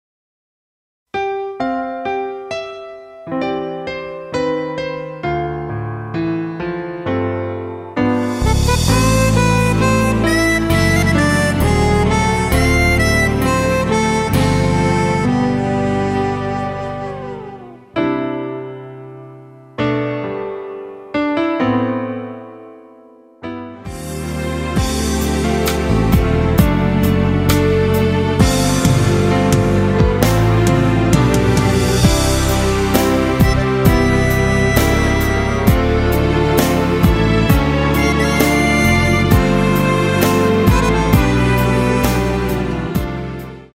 앞부분30초, 뒷부분30초씩 편집해서 올려 드리고 있습니다.
중간에 음이 끈어지고 다시 나오는 이유는